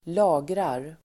Uttal: [²l'a:grar]